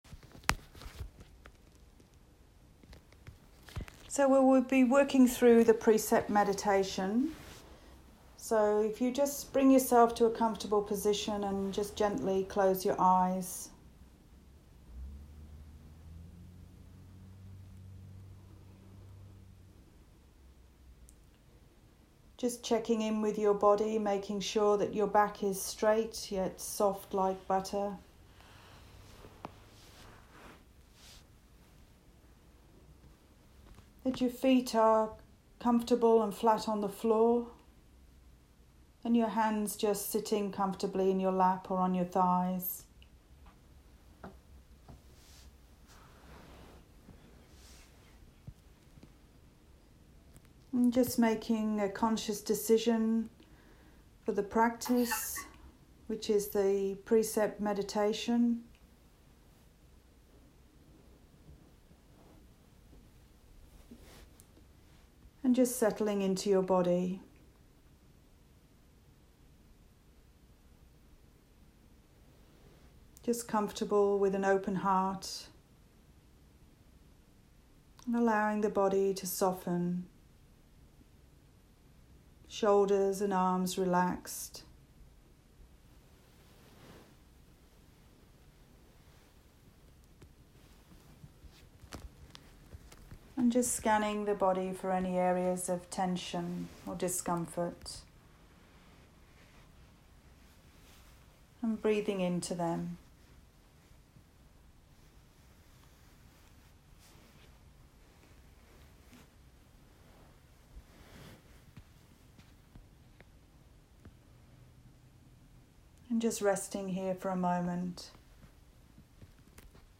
Precept Meditation – In mind chanting
Reiki-Precept-meditation.m4a